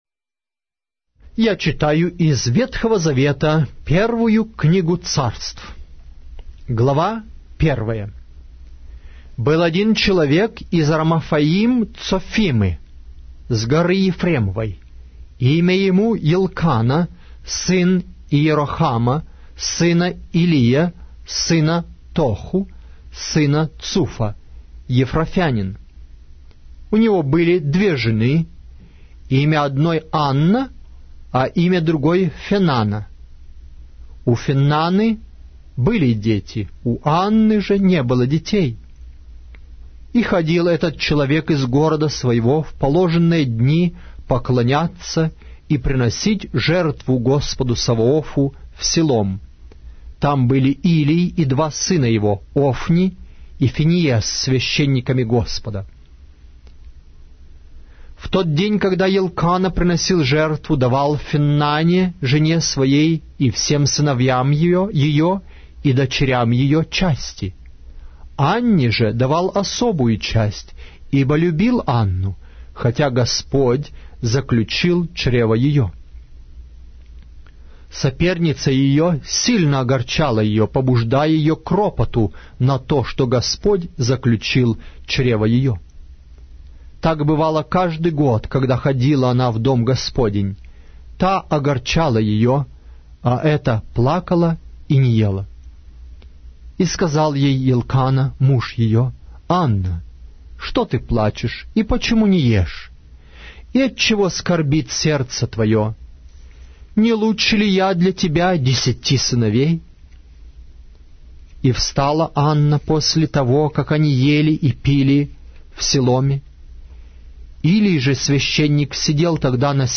Глава русской Библии с аудио повествования - 1 Samuel, chapter 1 of the Holy Bible in Russian language